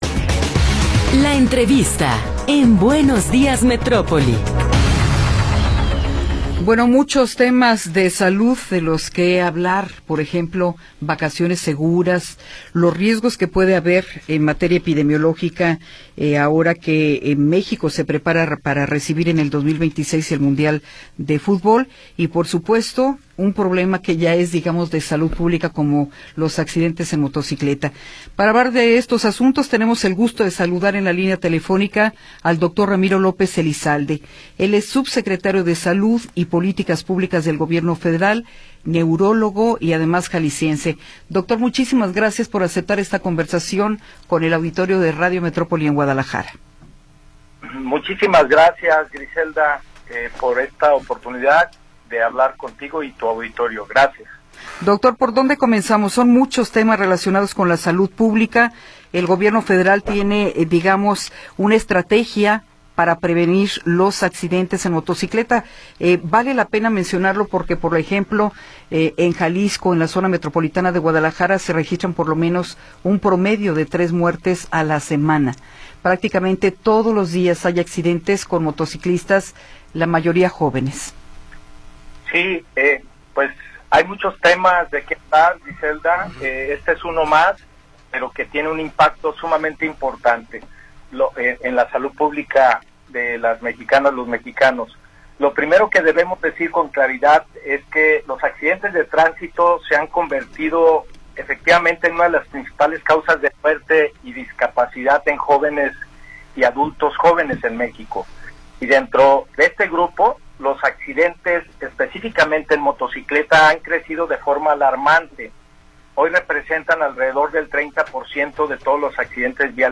Entrevista con Ramiro López Elizalde
Ramiro López Elizalde, Subsecretario de Políticas de Salud y Bienestar Poblacional, nos habla sobre diversos temas relacionados con la salud pública.